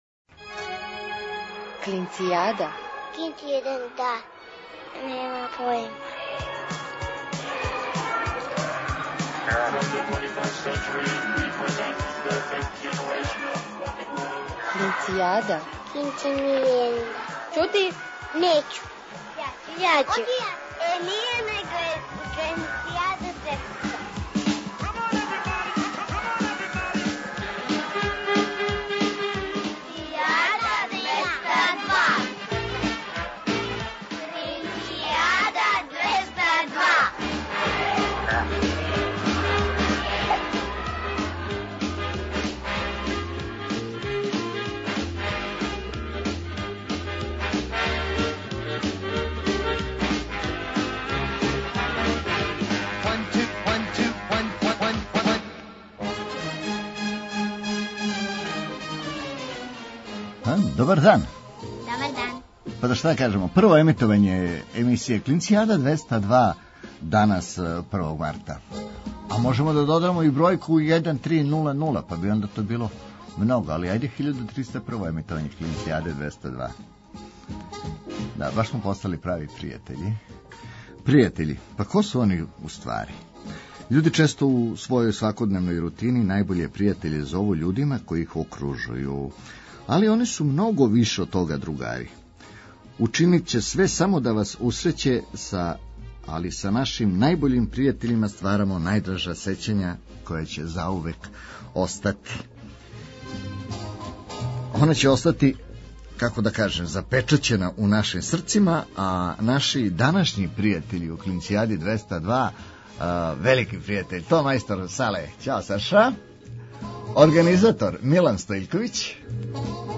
У њему тестирамо ваше познавање домаће и регионалне поп и рок музике, уз певање уживо у програму.